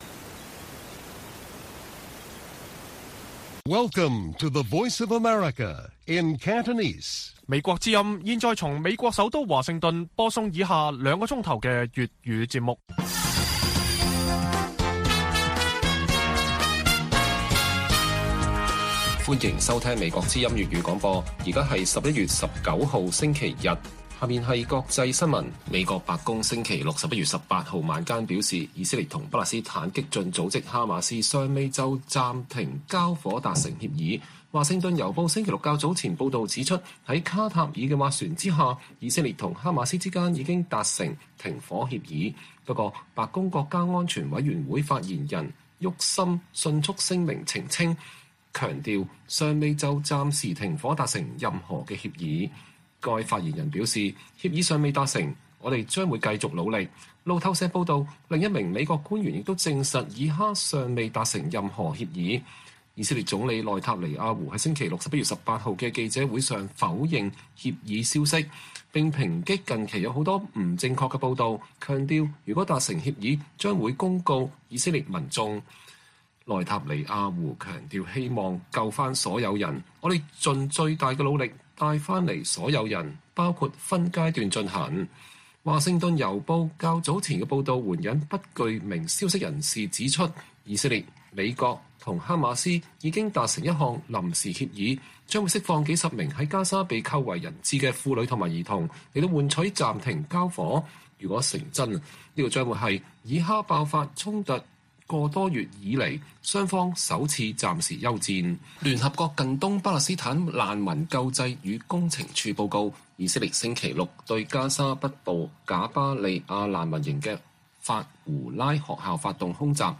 粵語新聞 晚上9-10點 : 傳以哈達成停火協議 白宮澄清：持續努力中